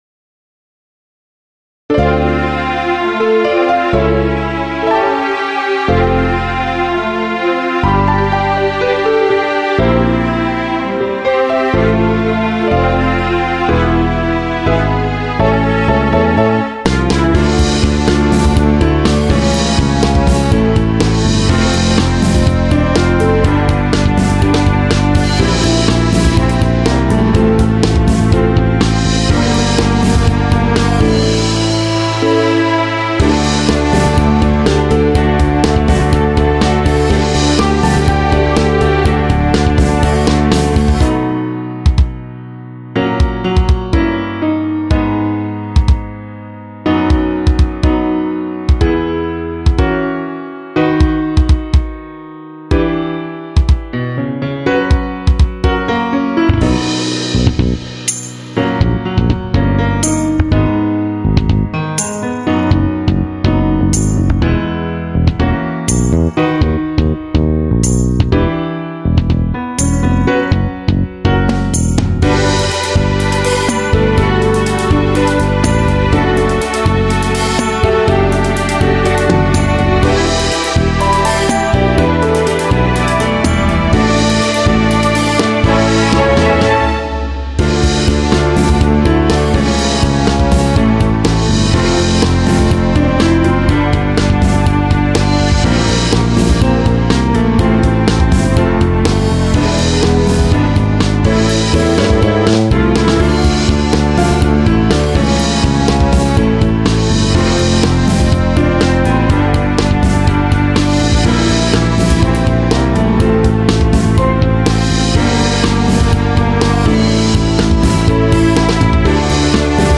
Drum/Bass/Piano/Pad/Strings